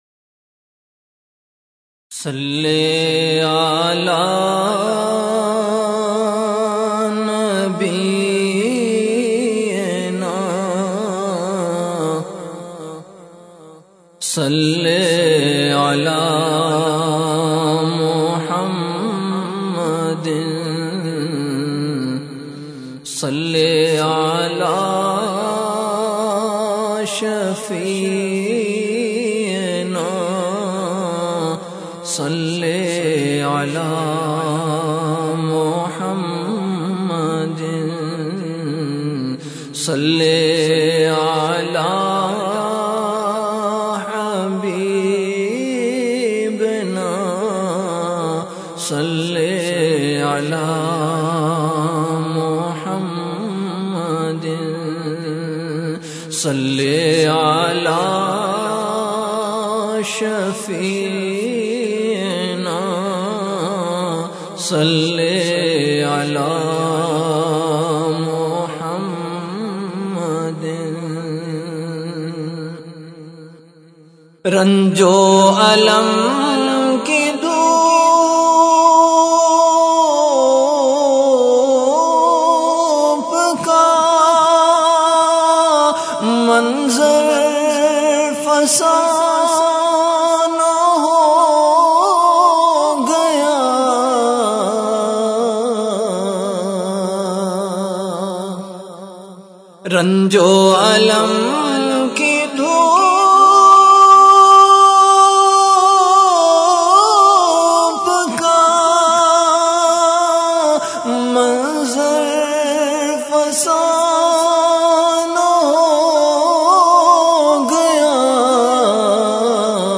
CategoryAshaar
VenueKhanqah Imdadia Ashrafia
Event / TimeAfter Asar Prayer